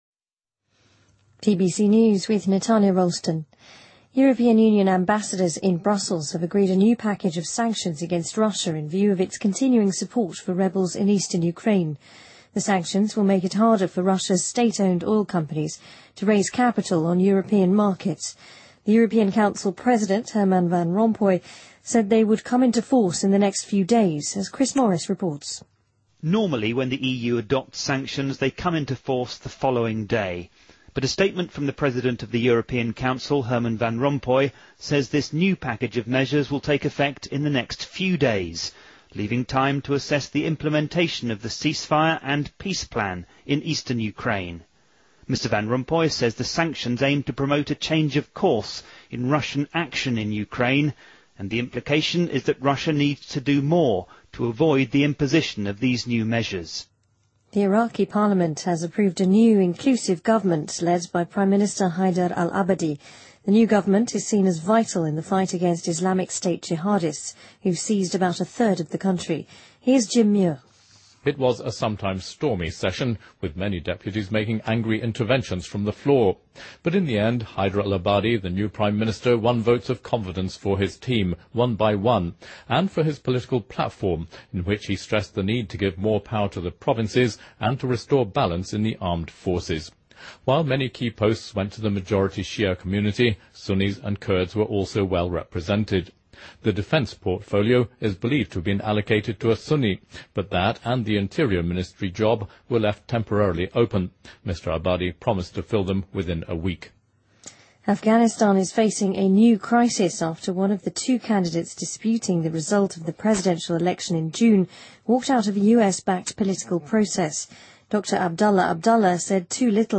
BBC news,剑桥公爵夫人第二次怀孕